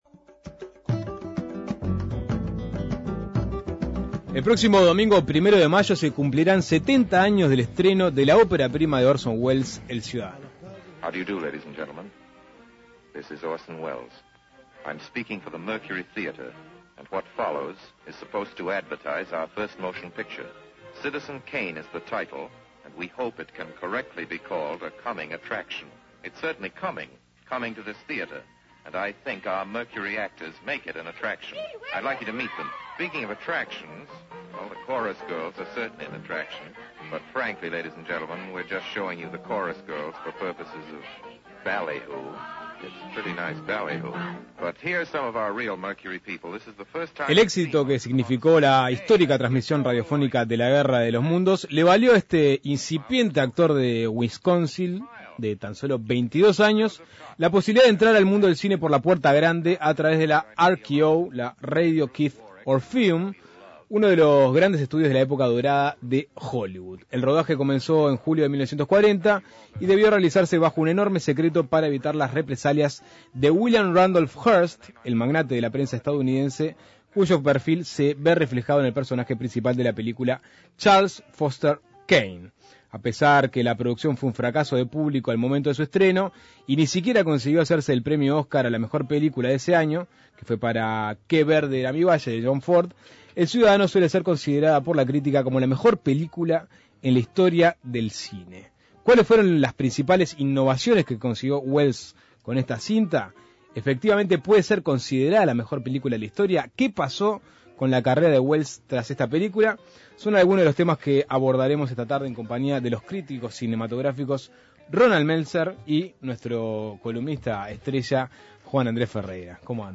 A pesar de que la producción fue un fracaso de público suele ser considerada como la mejor película en la historia del cine. Para conocerla en profundidad Suena Tremendo dialogó con los críticos cinematográficos